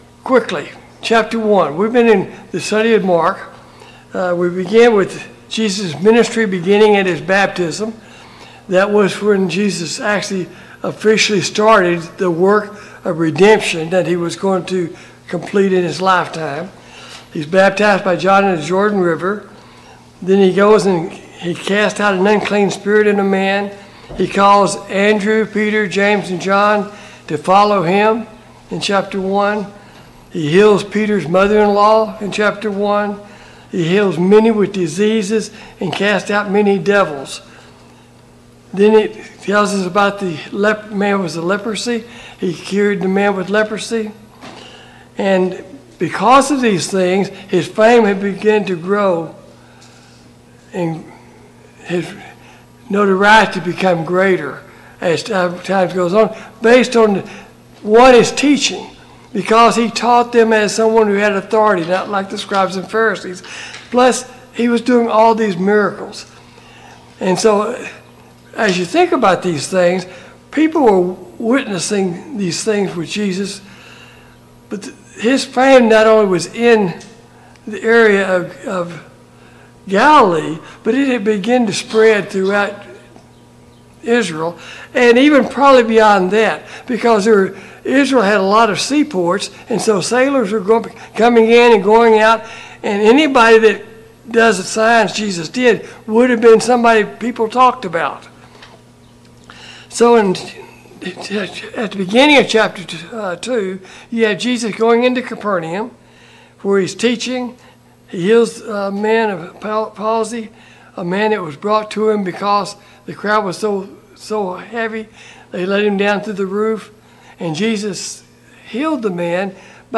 Passage: Mark 2 - 3 Service Type: Sunday Morning Bible Class